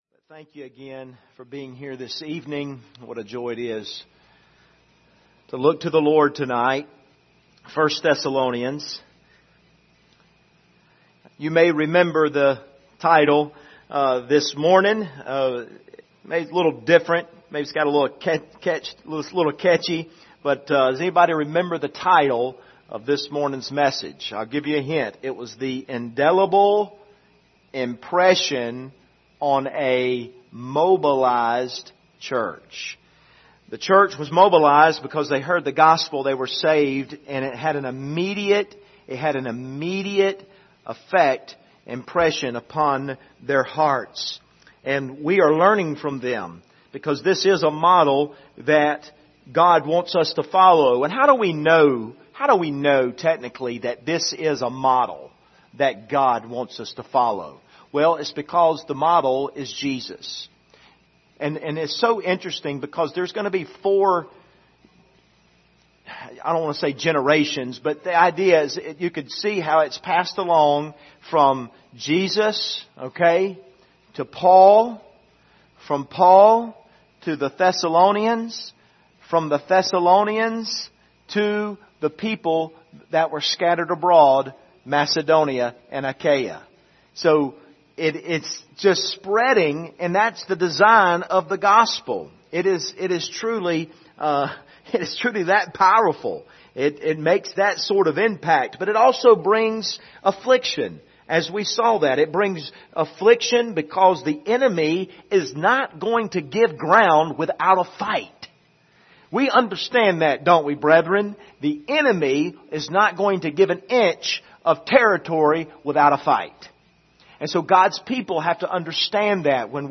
Passage: 1 Thessalonians 1:6-9 Service Type: Sunday Evening